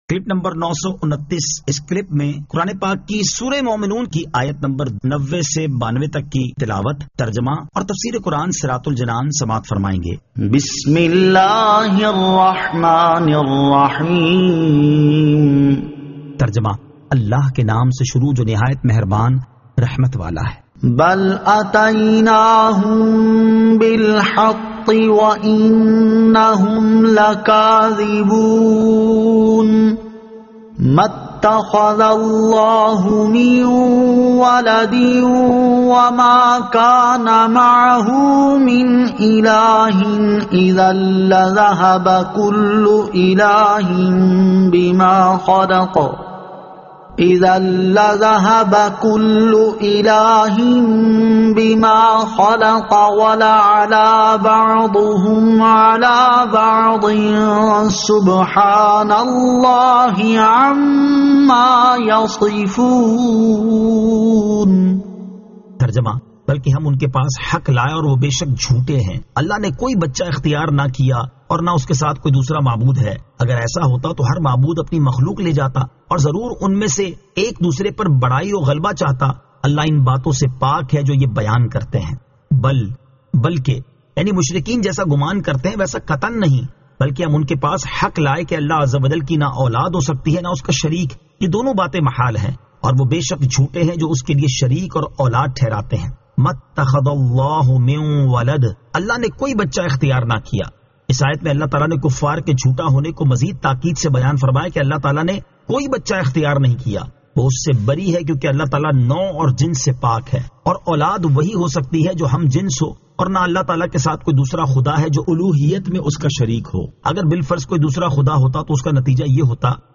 Surah Al-Mu'minun 90 To 92 Tilawat , Tarjama , Tafseer